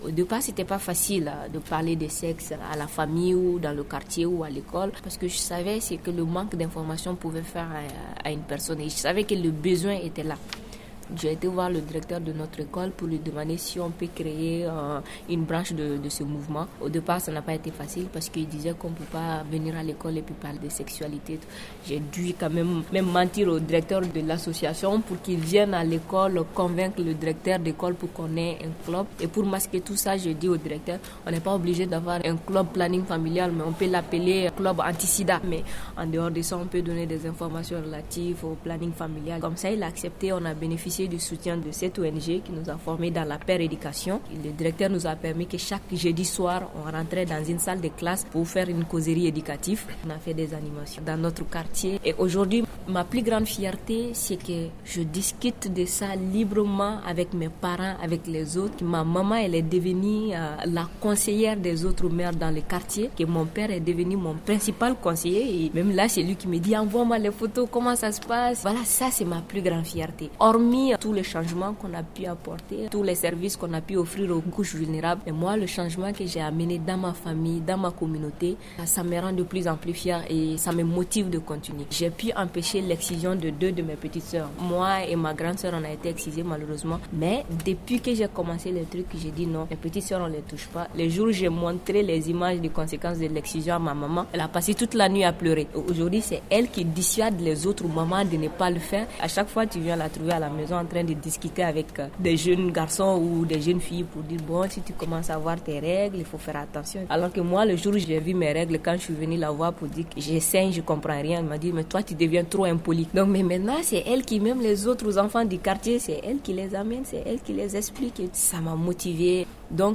Propos recueillis